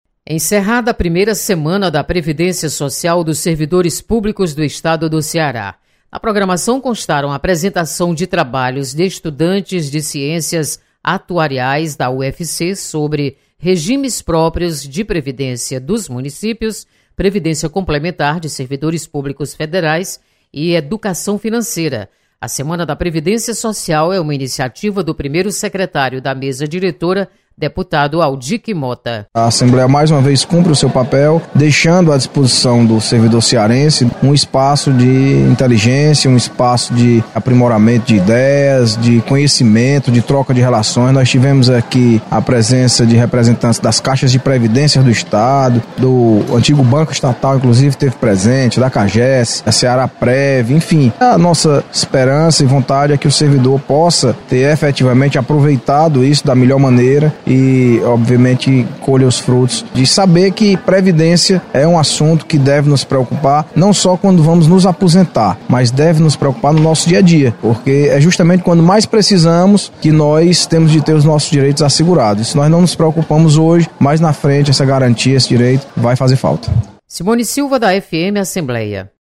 Você está aqui: Início Comunicação Rádio FM Assembleia Notícias Previdência